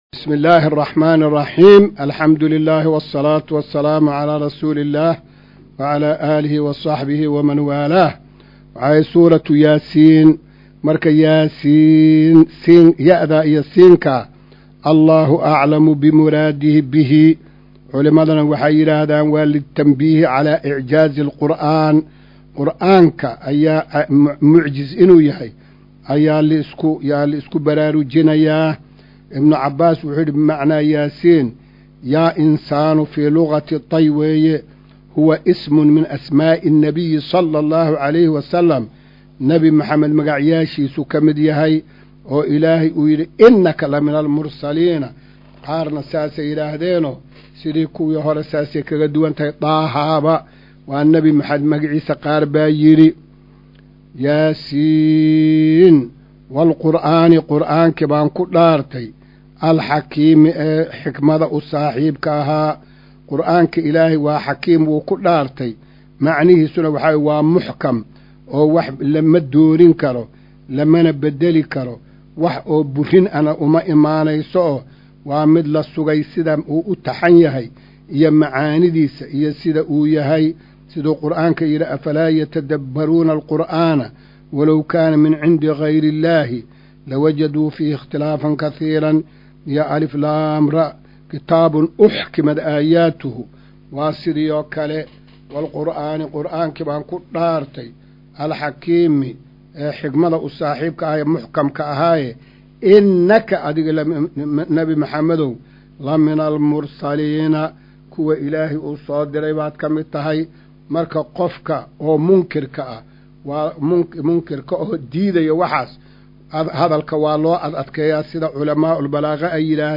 Maqal:- Casharka Tafsiirka Qur’aanka Idaacadda Himilo “Darsiga 208aad”